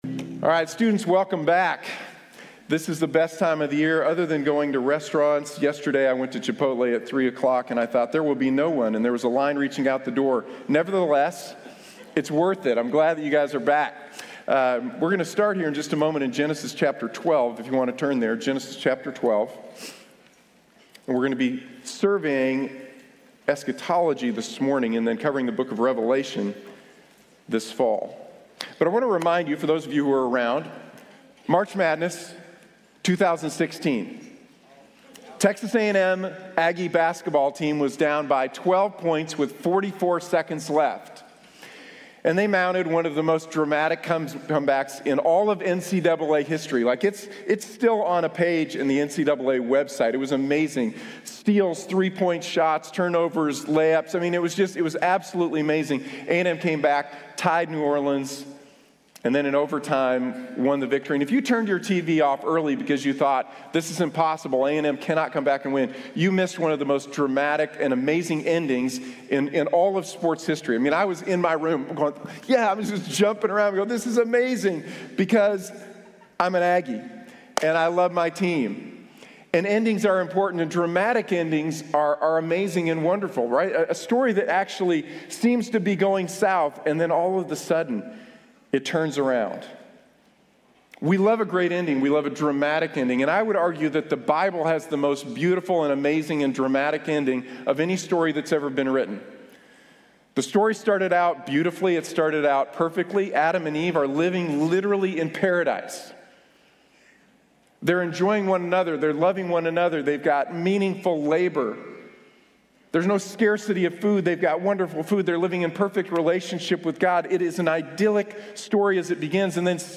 Eschatology: The End Is Near | Sermon | Grace Bible Church